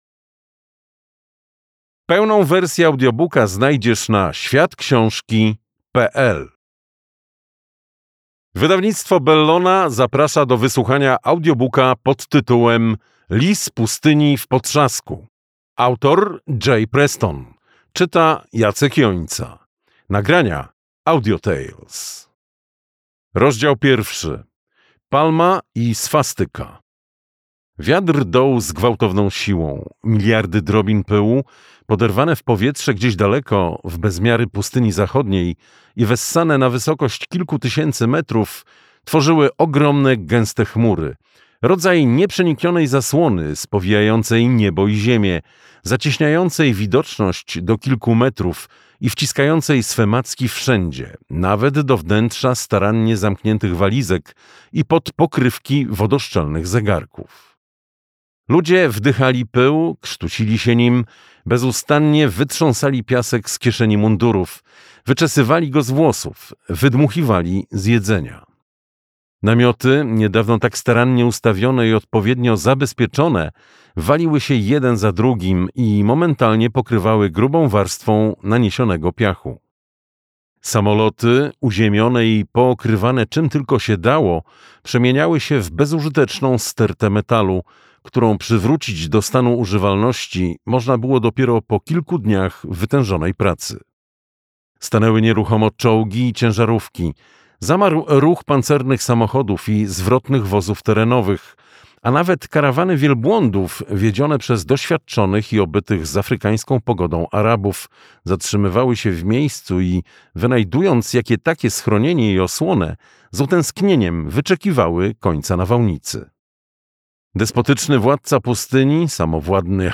Lis pustyni w potrzasku - J. Prestone - audiobook